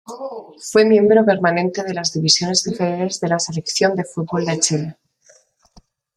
per‧ma‧nen‧te
/peɾmaˈnente/